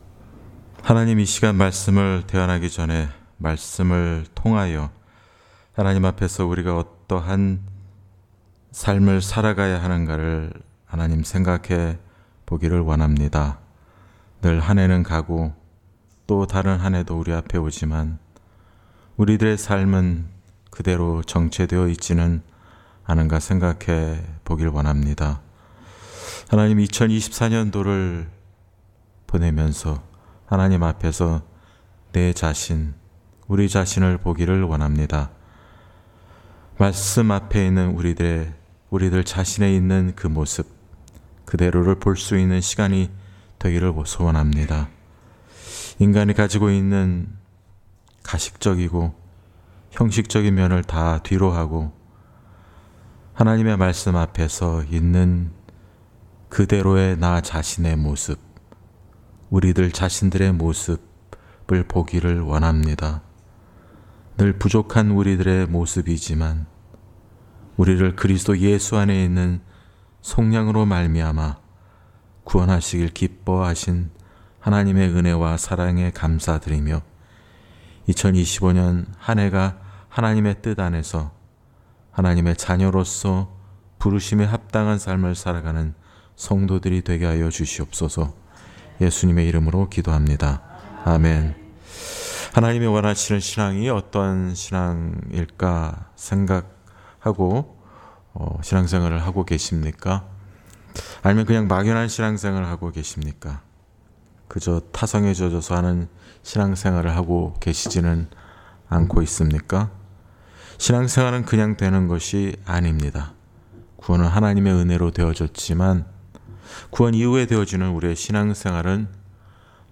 Series: 송구영신예배